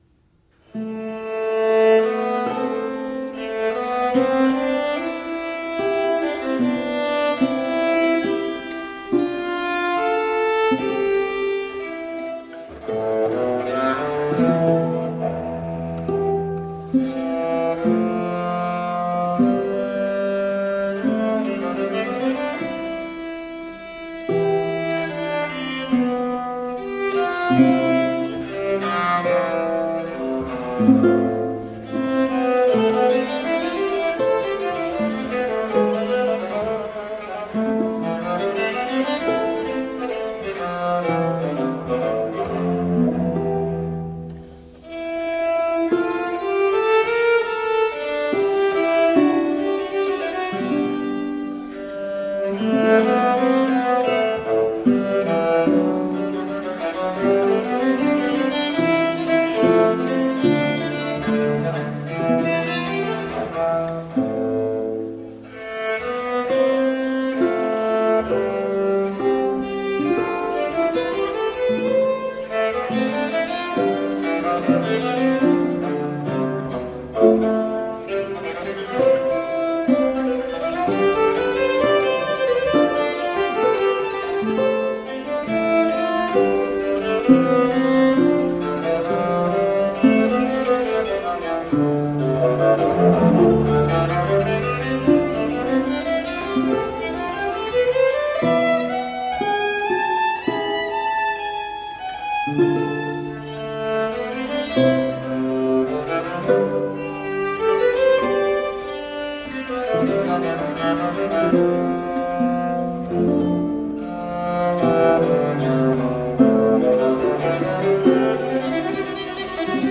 Classical, Renaissance, Instrumental
Lute, Viola da Gamba